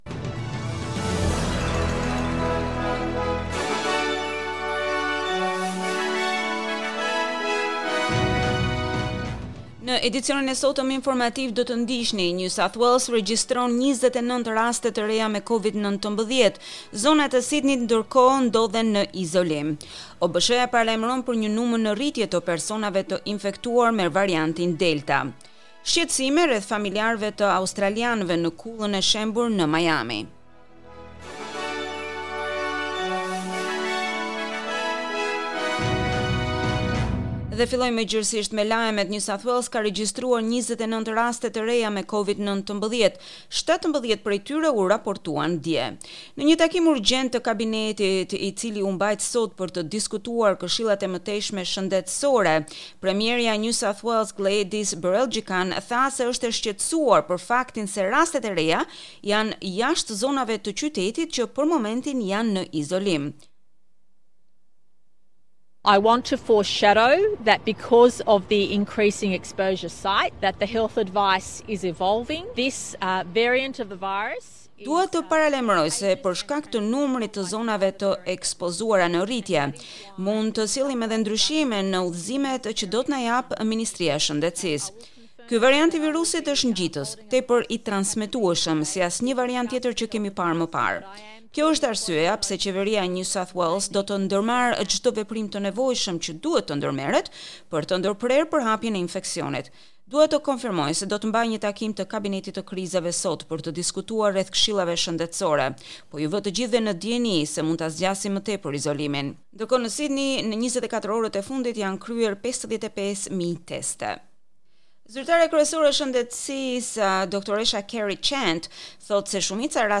SBS News Bulletin in Albanian - 26 June 2021